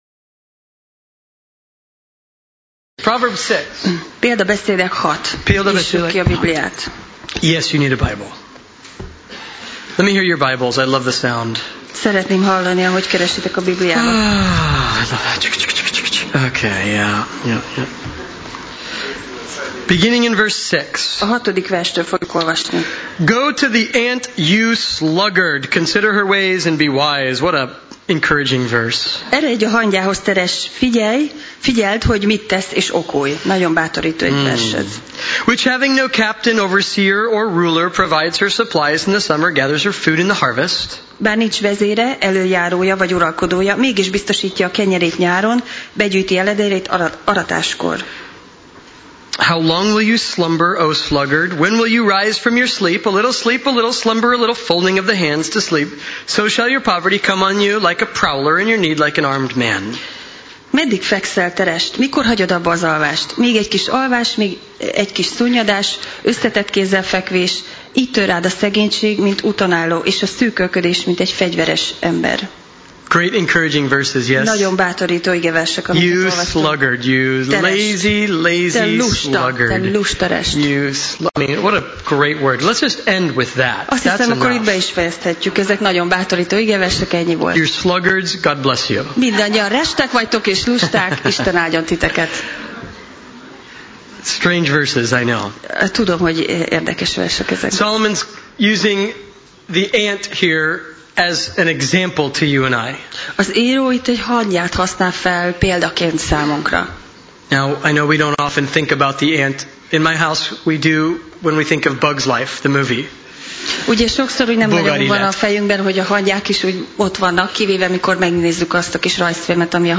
Sorozat: Példabeszédek Passage: Példabeszédek (Proverbs) 6 Alkalom: Szerda Este